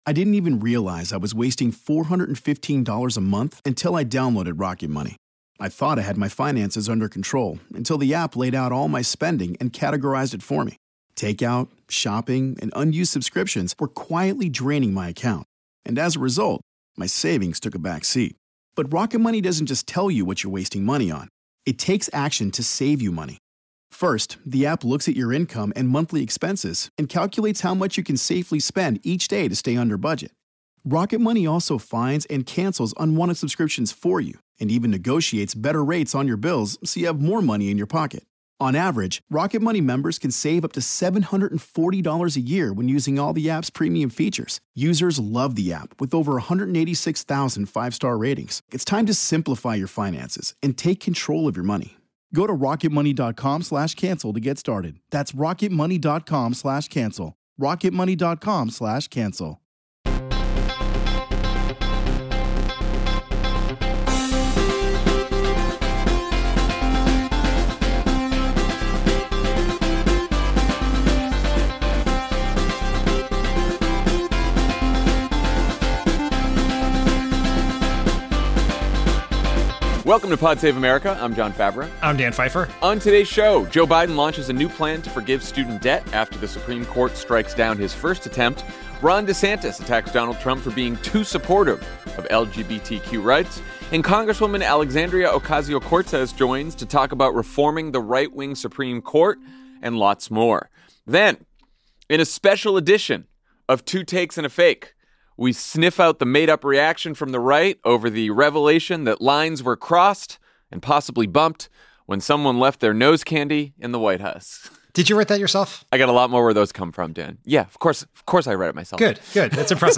Joe Biden launches a new plan to forgive student debt after the Supreme Court strikes down his first attempt. Ron DeSantis attacks Donald Trump for being too supportive of LGBTQ rights. Congresswoman Alexandria Ocasio-Cortez joins to talk about reforming the right wing Supreme Court and lots more.